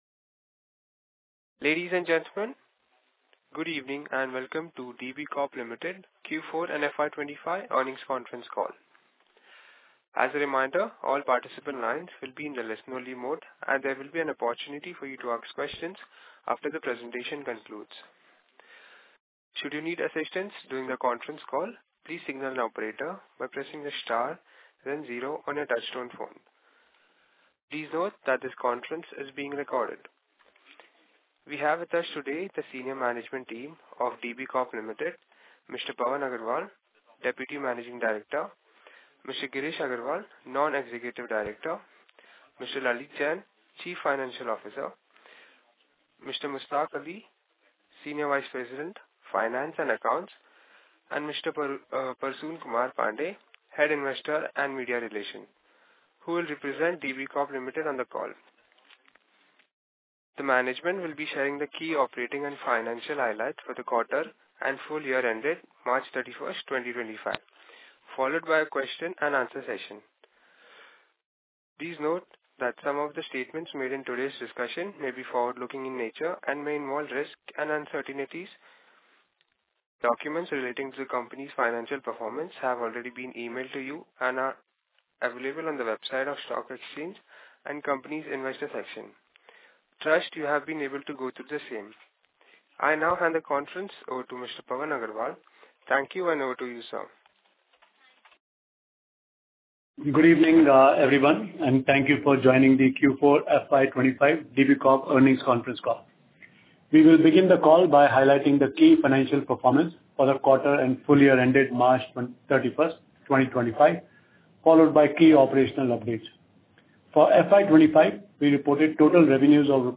Concalls
DB_Corp_Q4FY25_Concall_Audio.mp3